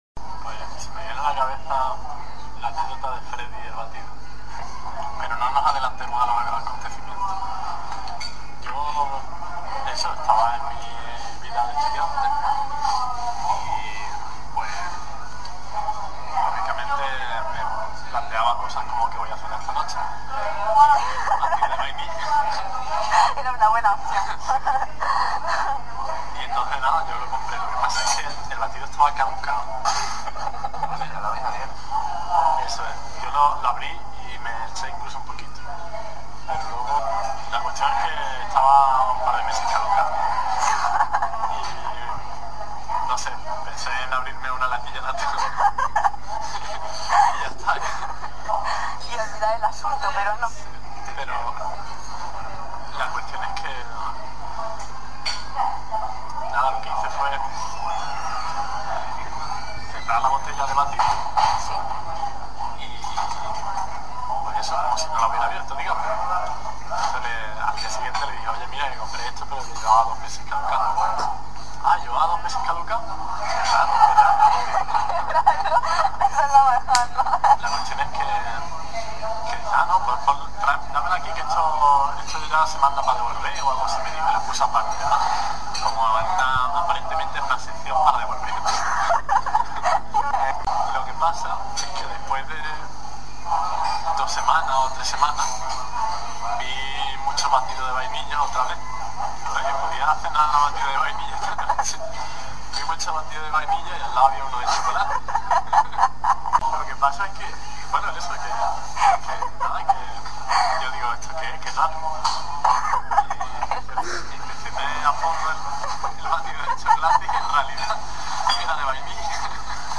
Anécdota del batido